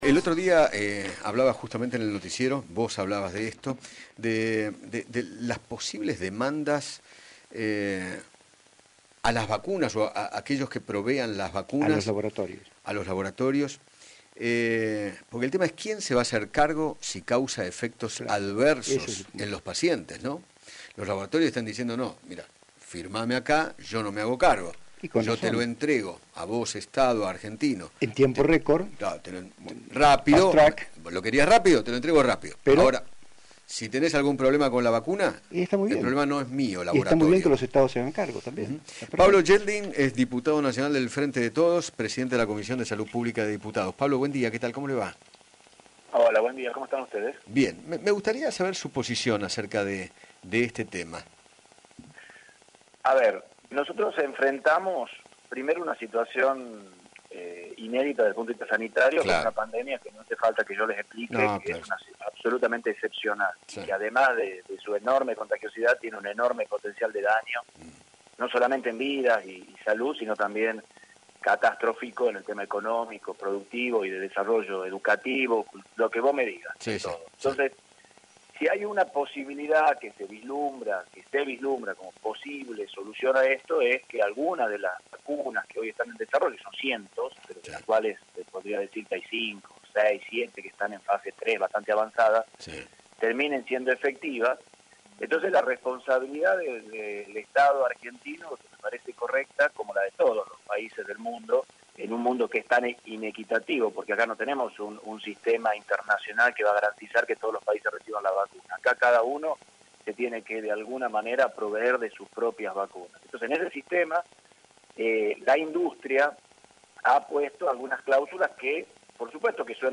Pablo Yeldin, Diputado Nacional, dialogó con Eduardo Feinmann sobre la responsabilidad de los laboratorios y del Estado en el caso de que las vacunas contra el COVID-19 provoquen efectos adversos y explicó que “algunos piden doble jurisdicción”.